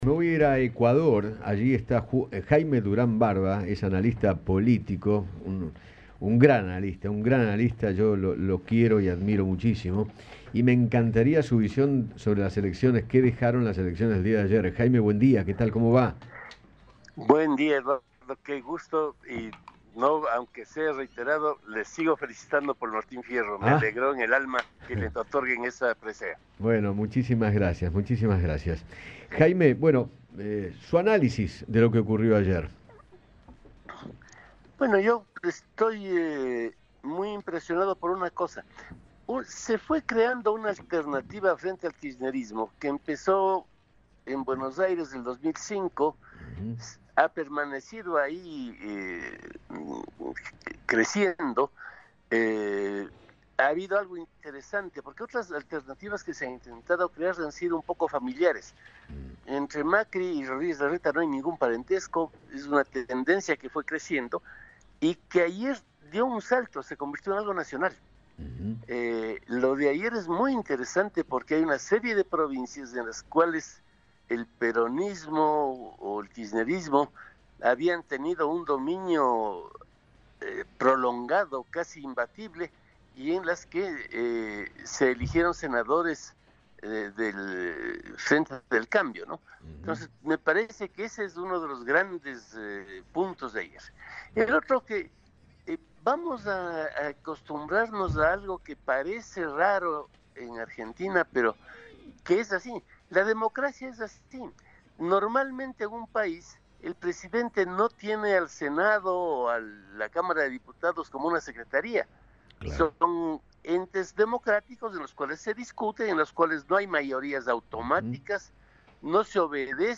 Jaime Durán Barba, consultor de imagen y asesor político, dialogó con Eduardo Feinmann sobre las elecciones de ayer y aseguró que “vamos a vivir cámaras con un equilibrio de fuerzas muy interesantes”.